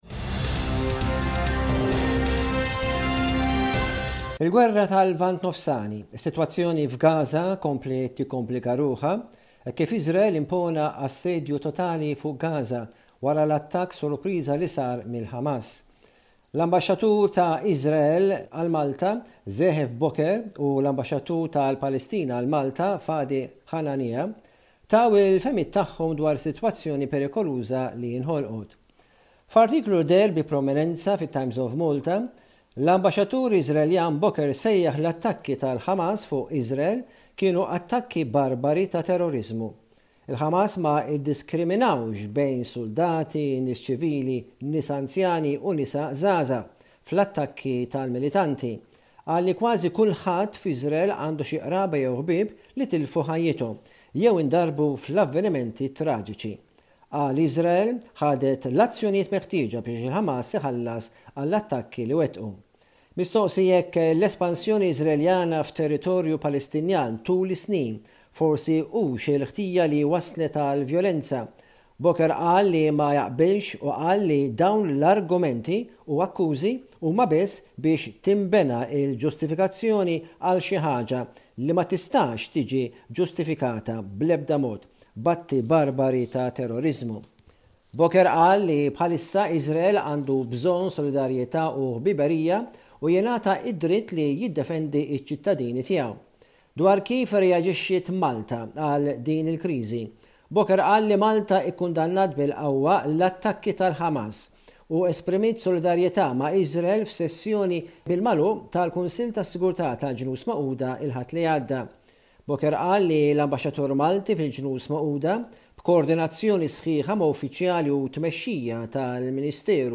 SBS Radio correspondent